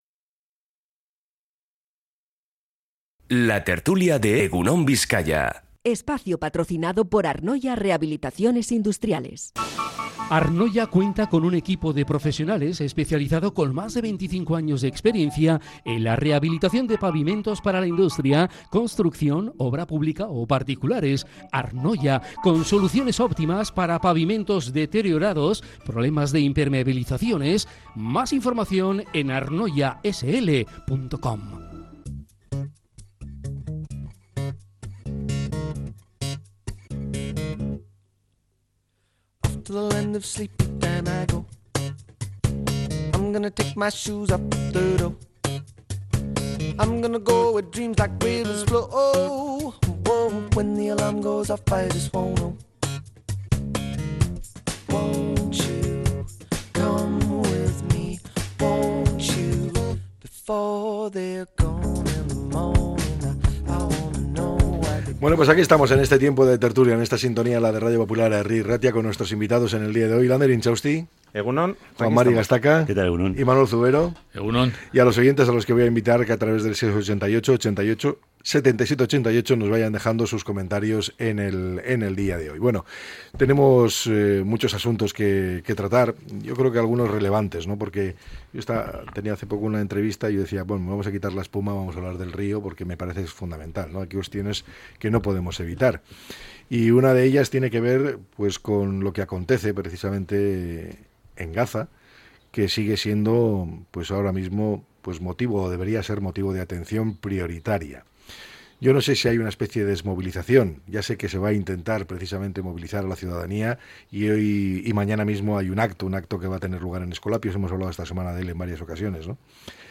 La tertulia 30-05-25.